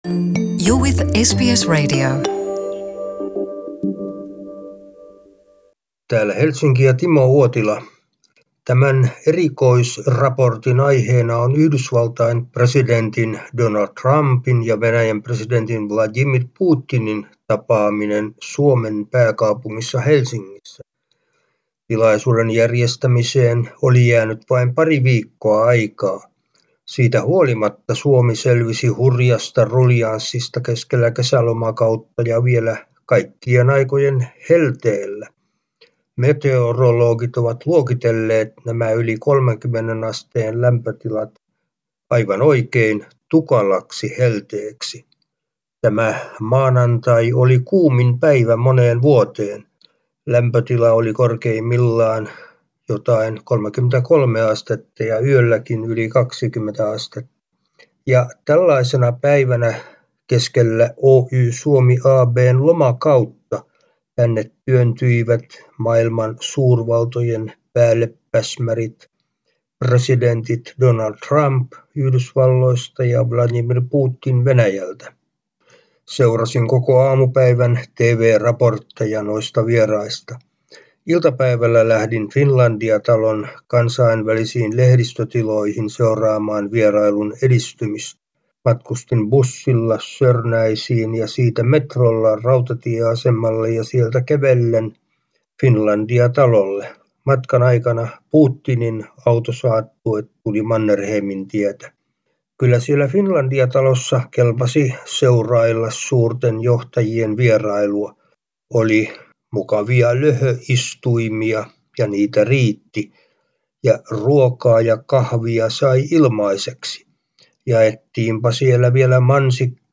raportoi Helsingistä